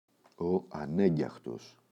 ανέγγιαγος [a’neɟaγos]